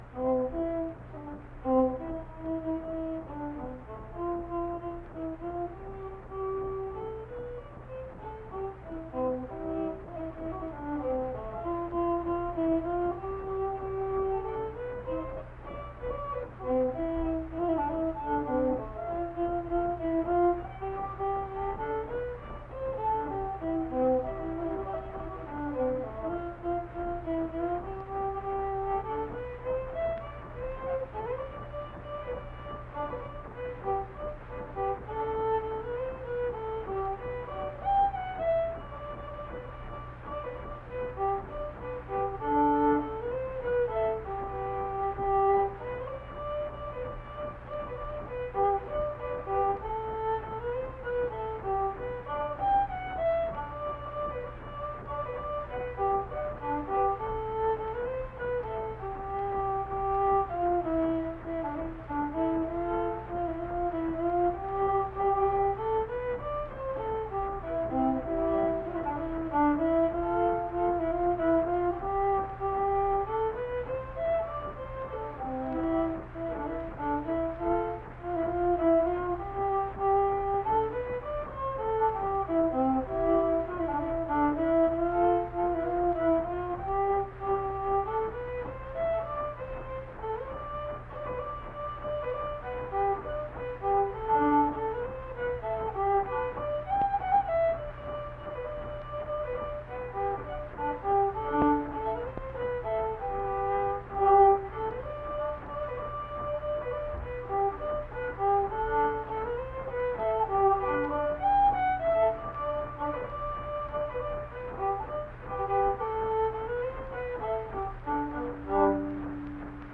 Unaccompanied fiddle music
Verse-refrain 2(2).
Instrumental Music
Fiddle